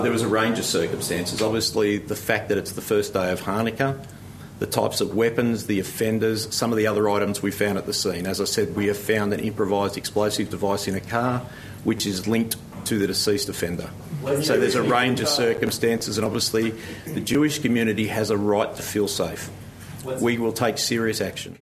Police Commissioner, Mal Lanyon says the shooting has been designated a ‘terrorist incident’: